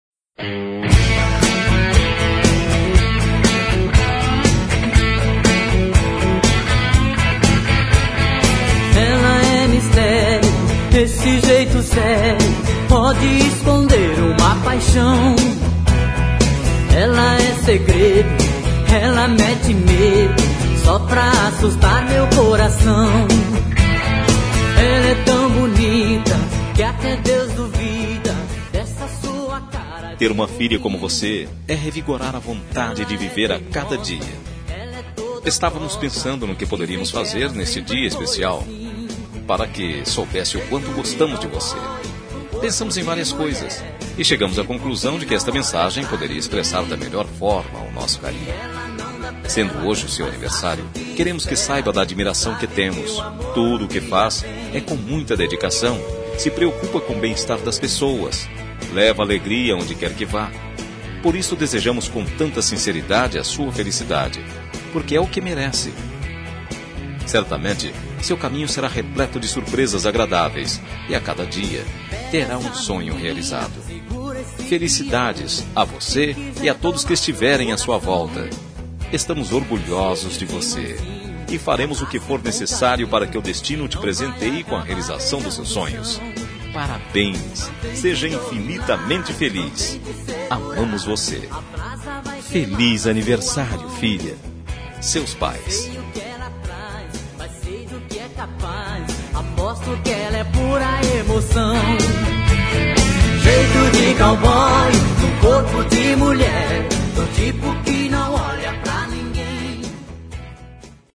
Telemensagem de Aniversário de Filha – Voz Masculina – Cód: 1798